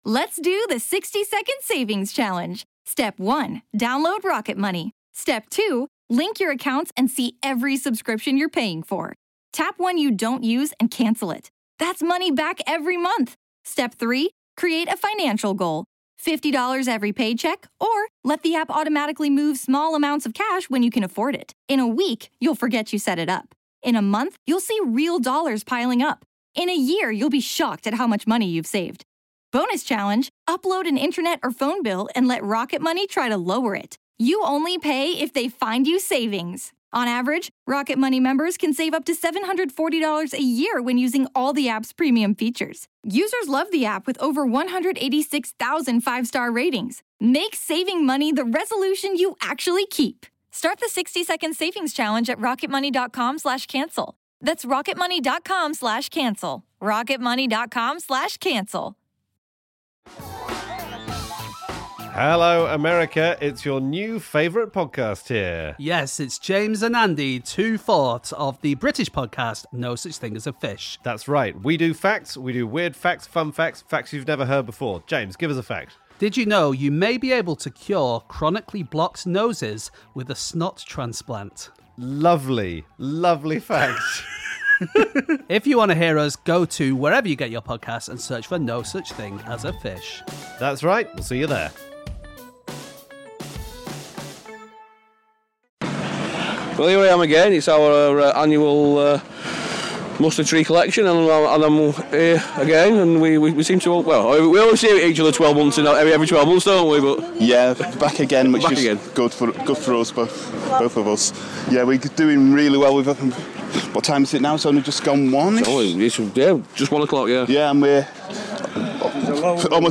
Interview
during FC United's Big Coat Day